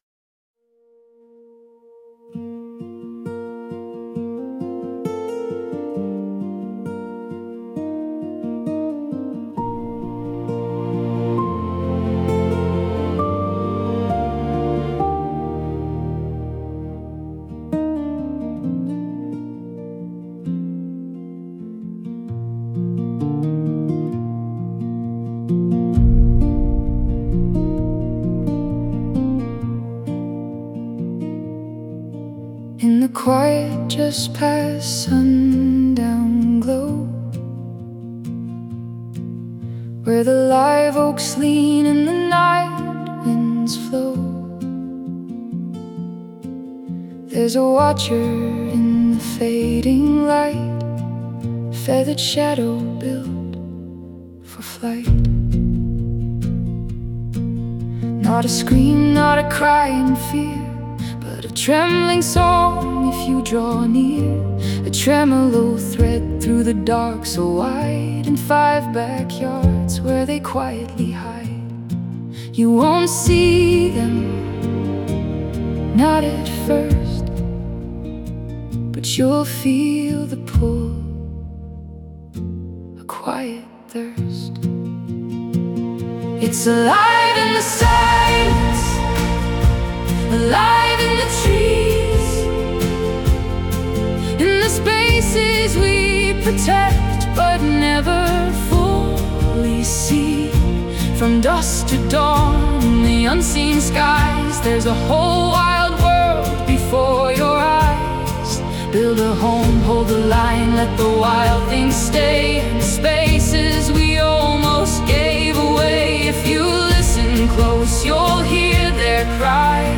Nine songs inspired by our backyard owls and created with artificial intelligence.
A warm, hopeful song about dusk, listening closely, and discovering that a hidden wild world may begin just beyond your own fence line.